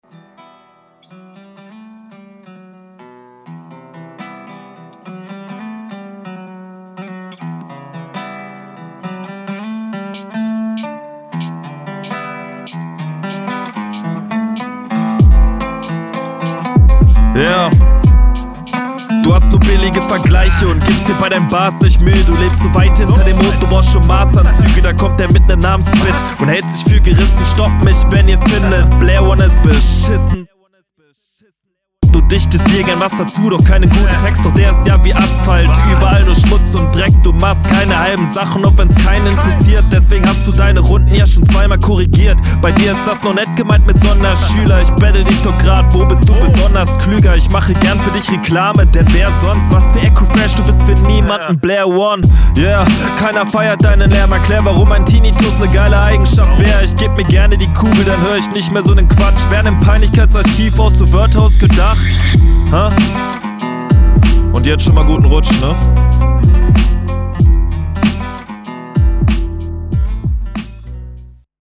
Einstieg direkt cooler.
Flow strong, Mix sehr gut, Punches ganz gut, Reime gehen klar